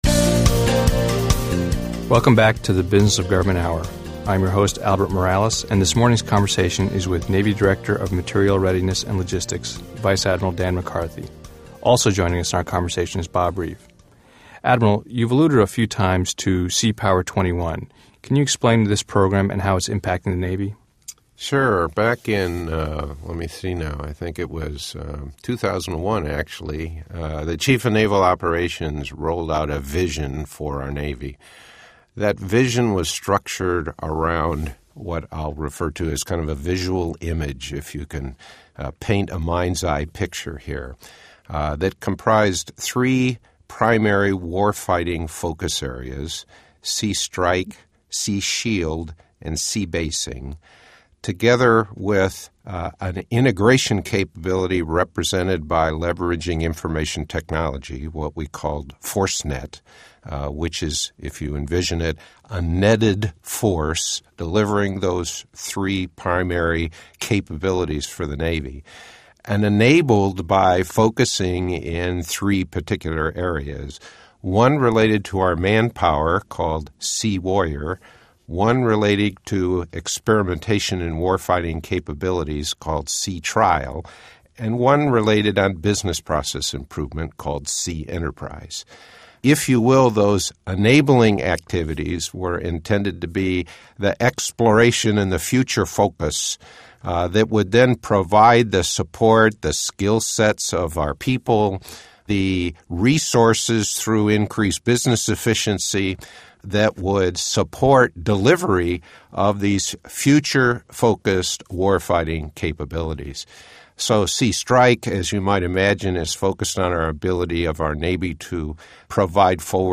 Interviews | IBM Center for The Business of Government
Guest: Vice Admiral Justin D. McCarthy